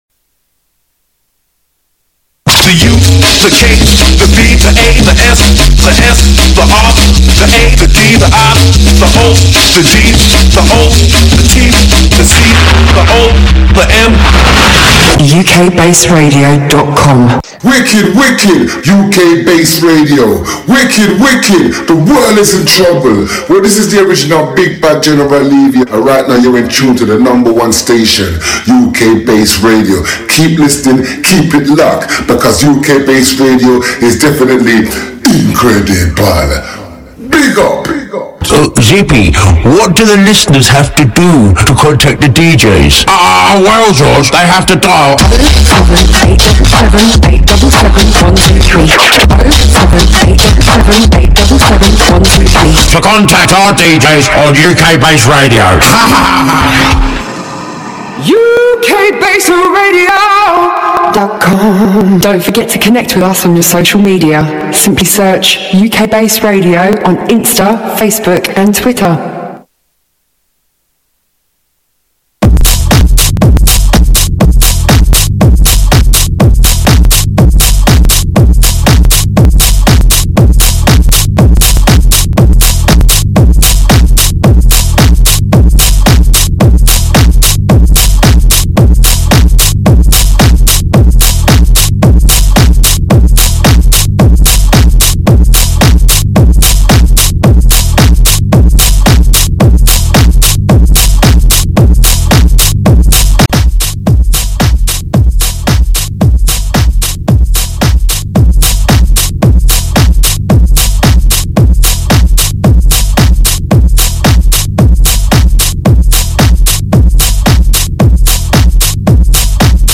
Underground House Music